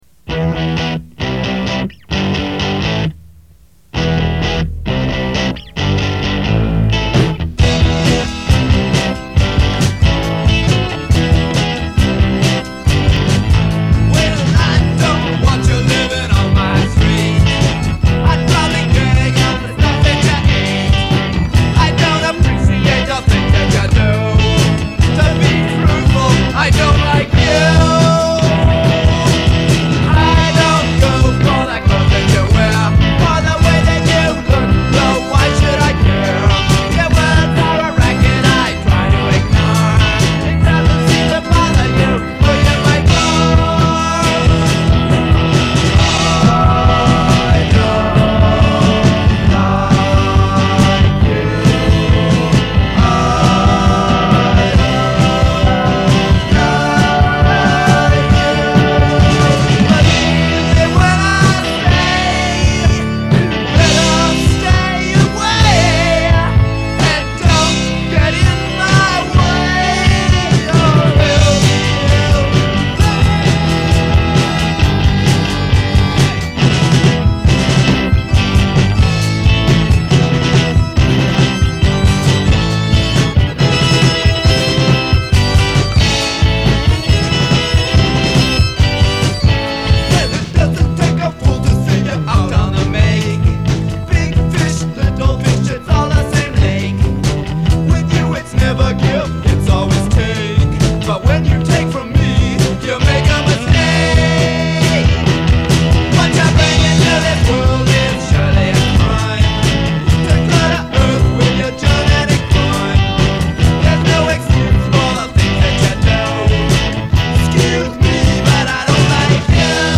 keyboard
bass
drums
guitar, vocals